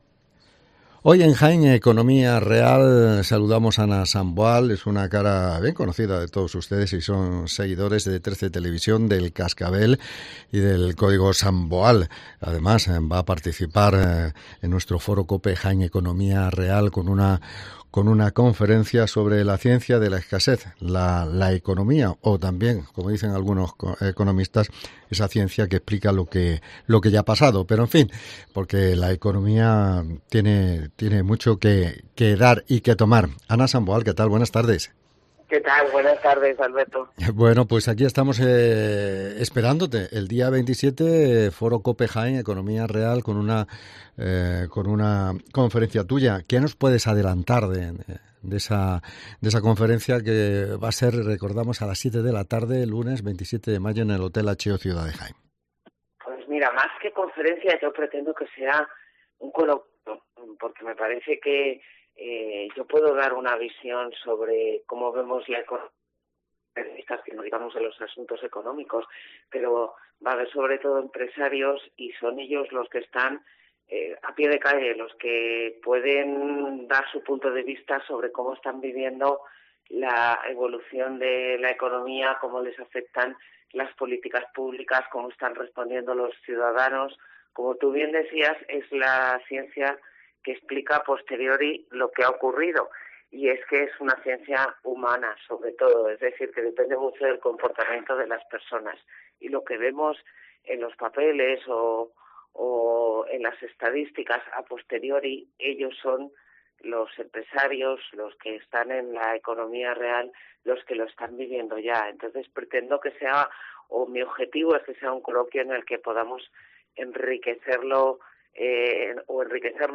Hablamos con Ana Samboal